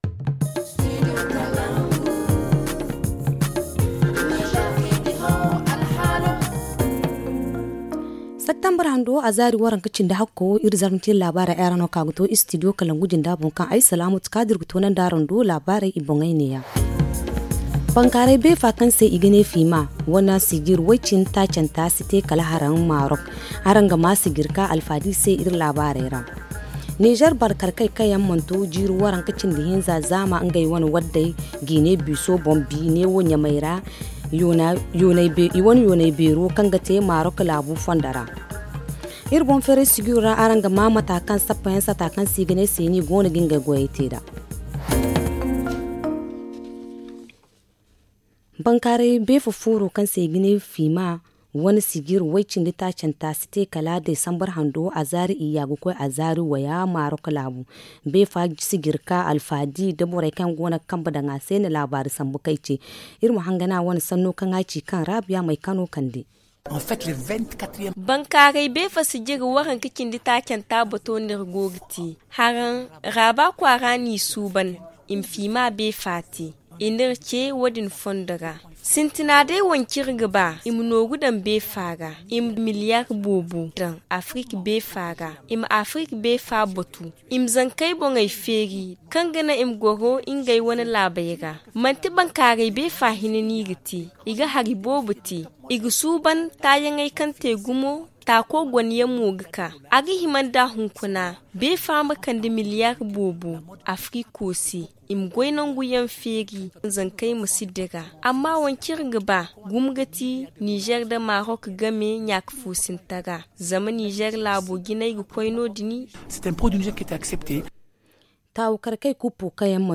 Le journal en français